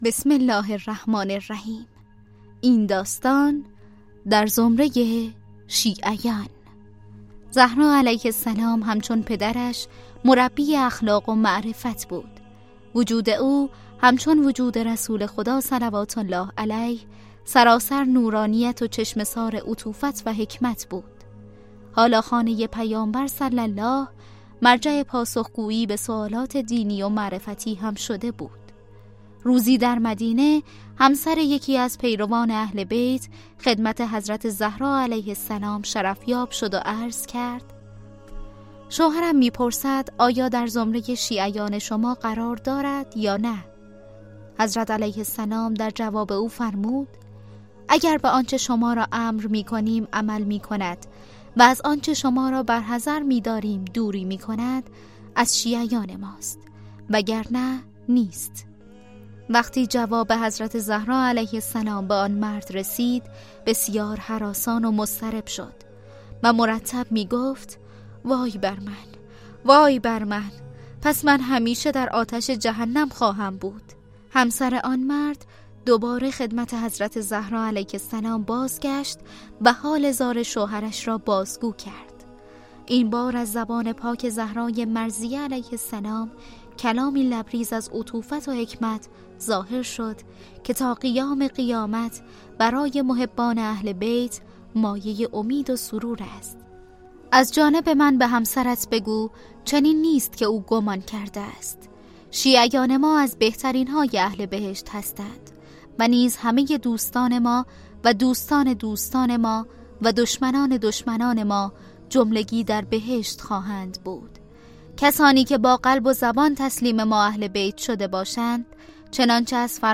کتاب صوتی مهربانو